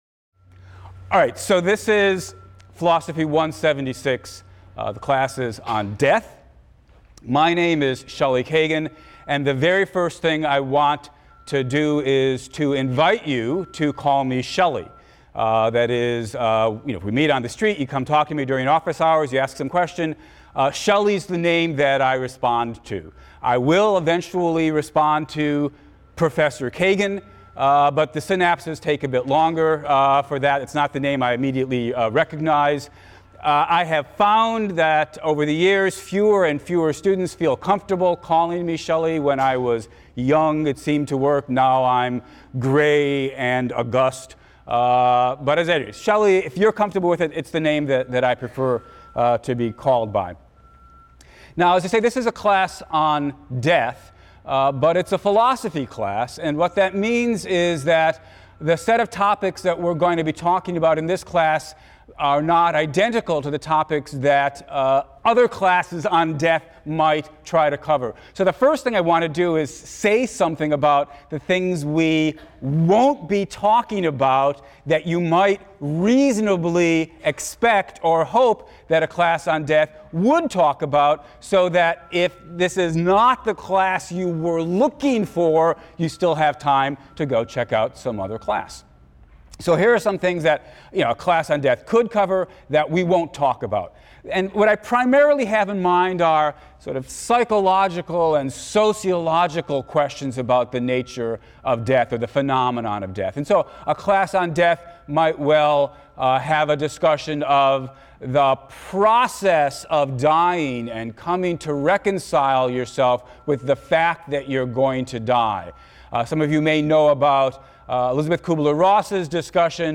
PHIL 176 - Lecture 1 - Course Introduction | Open Yale Courses